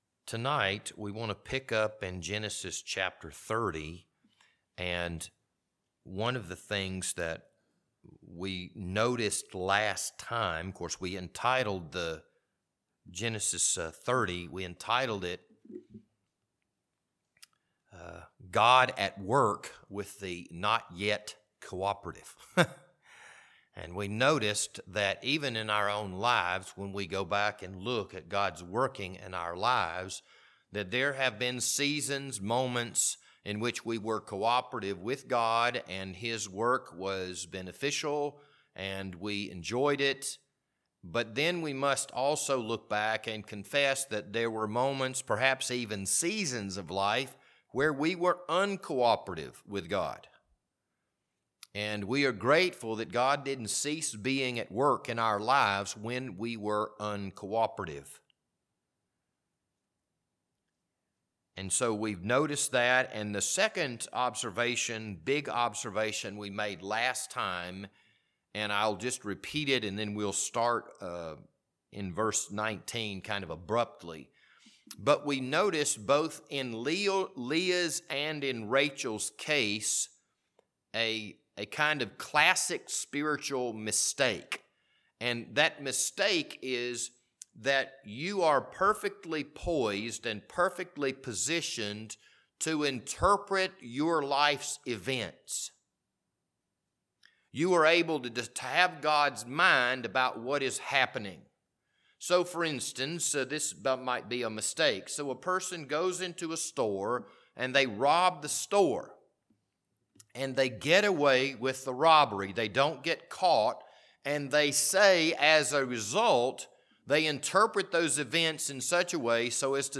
This Wednesday evening Bible study was recorded on September 6th, 2023.